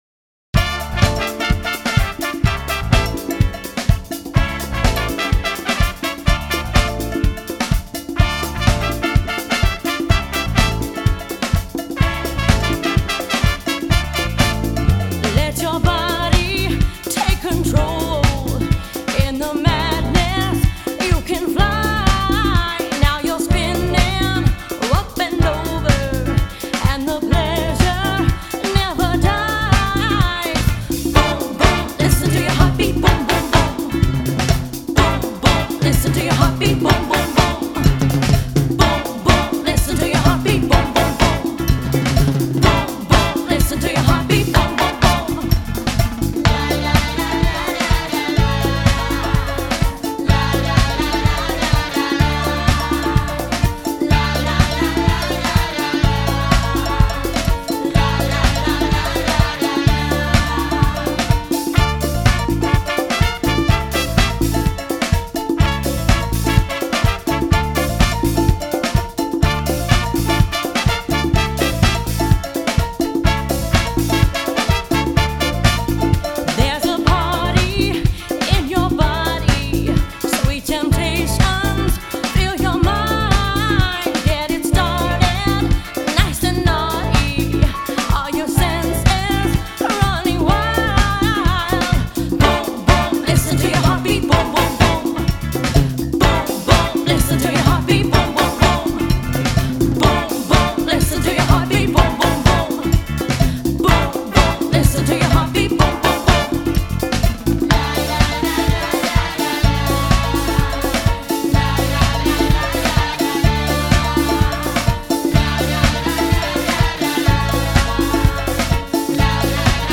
ethnic band